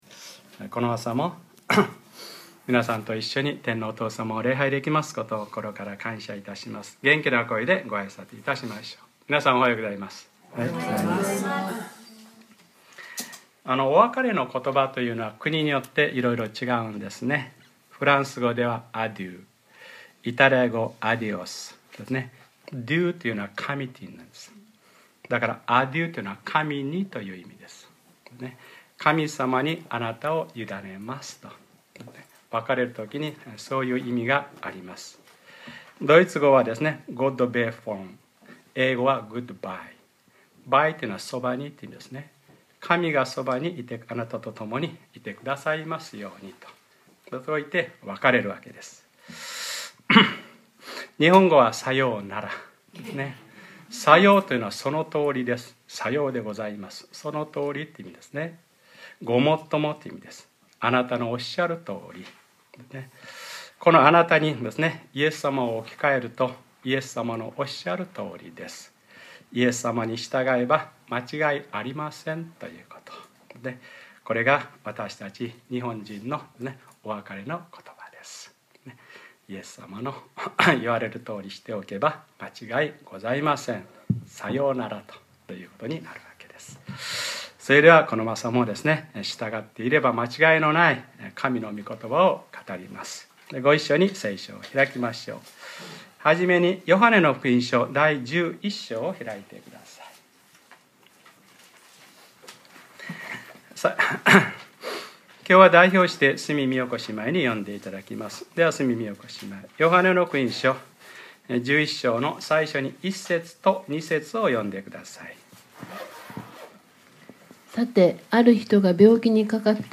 2012年11月4日(日）礼拝説教 『なお2日とどまられた』